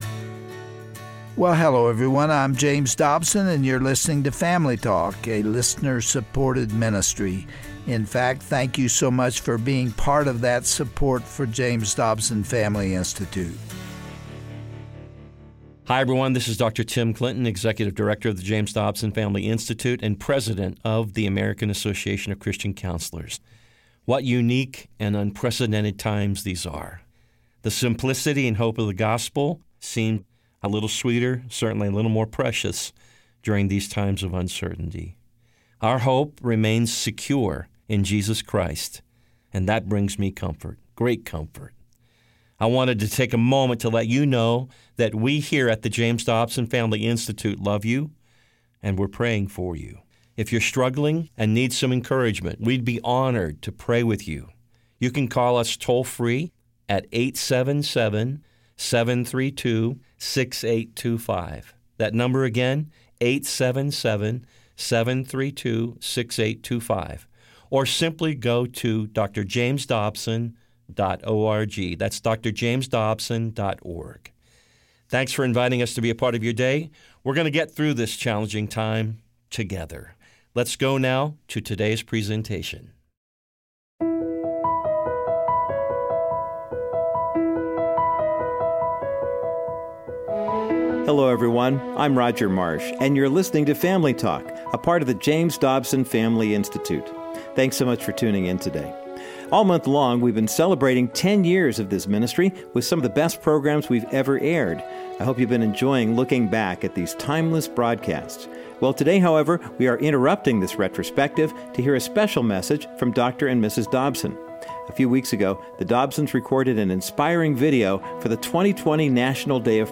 In the midst of this global, unprecedented crisis, what is God trying to say to His people? On this special Family Talk broadcast, Dr. Dobson and his wife Shirley provide timeless biblical wisdom for all of us enduring this pandemic. They talk about Gods love and great compassion for all humanity, while Dr. Dobson shares why he thinks this may be the beginning of the third Great Awakening.